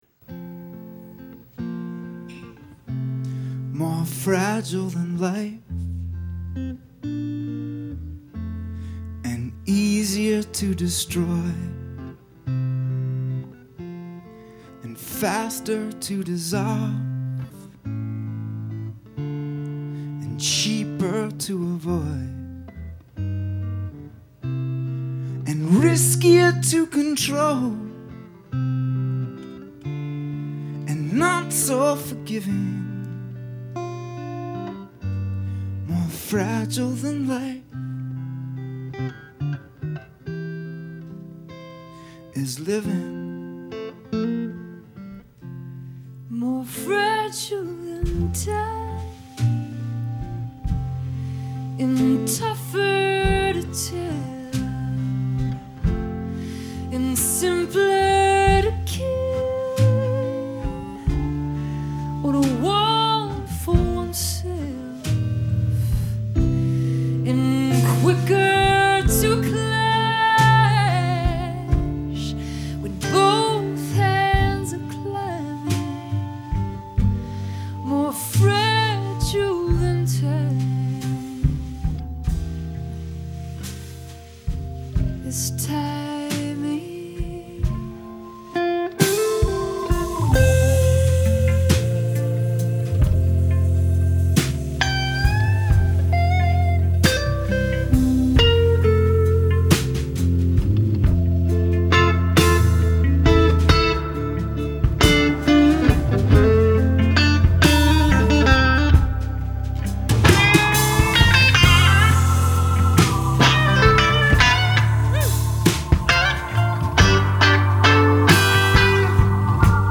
… recorded alive at the living room in nyc